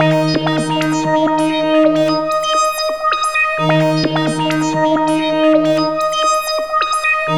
Hands Up - K2 Synth.wav